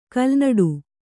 ♪ kalnaḍu